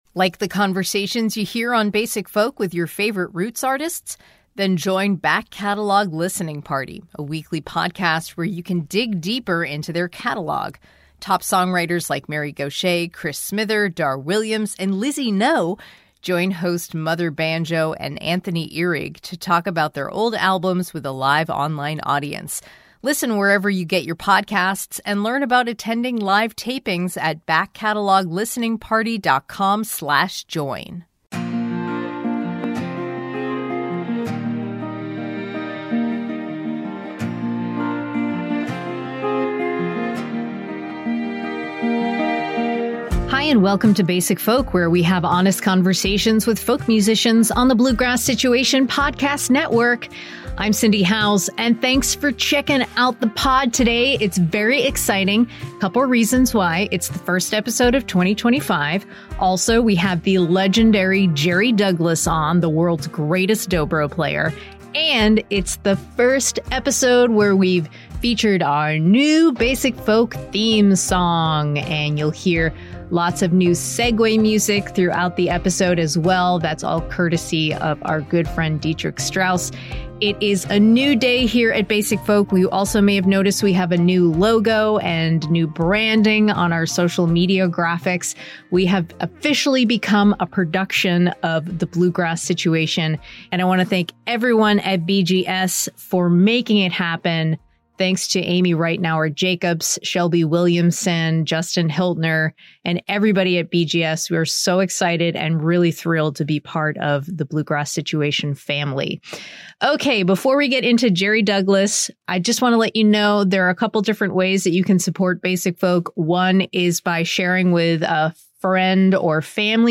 In our Basic Folk conversation, he shares stories about his upbringing in Warren, Ohio, where his father’s steel mill job and love for music instilled in him a strong work ethic and a passion for playing. He also talks about getting scouted as a teenager by The Country Gentlemen, one of the greatest bluegrass bands ever, who eventually took young Jerry on tour.